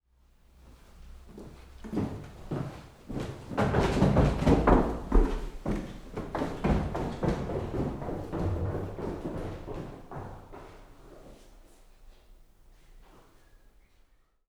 Toutefois, voici quelques exemples anonymes de sons qui ont accompagné un témoignage.
Descente-escalier.wav